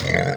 ZomBear Hurt.wav